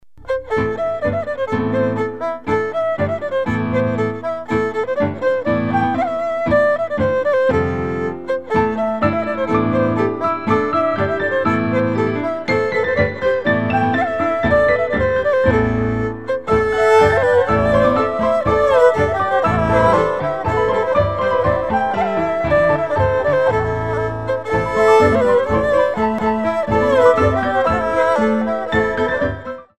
13 InstrumentalCompositions expressing various moods.